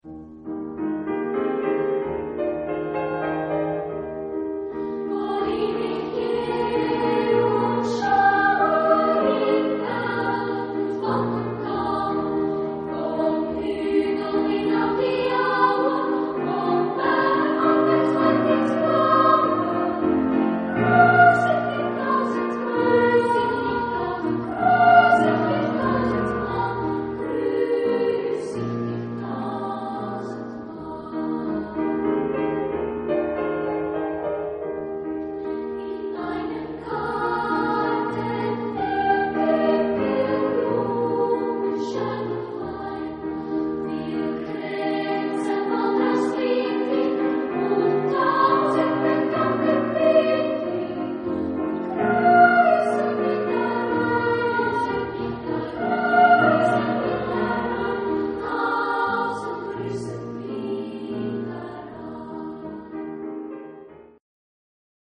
3 Lieder für Frauenchor und Klavierbegleitung
Genre-Stil-Form: weltlich ; romantisch ; Lied
Instrumentation: Klavier  (1 Instrumentalstimme(n))
Tonart(en): Es-Dur